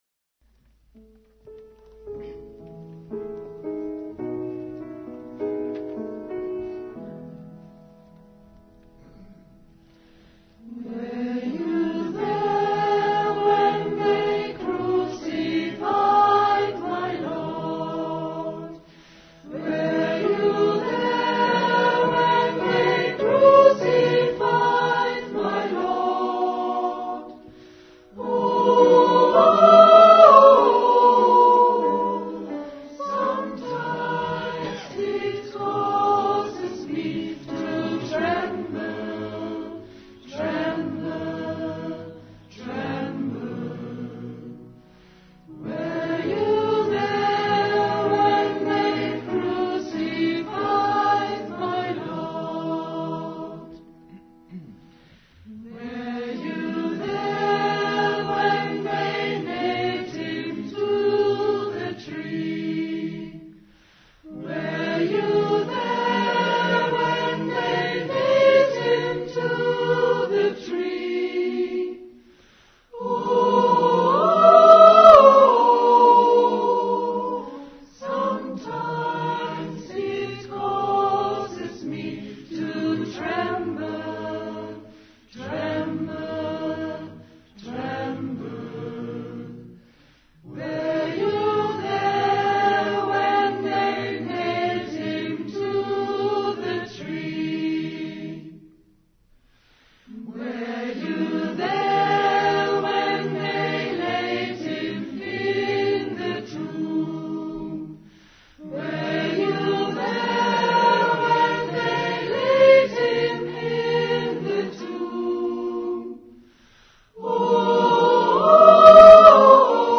Predigt vom 22. April 2011 Predigt Predigttext: Kolosser 1, 12-23 12 Dankt dem Vater mit Freude!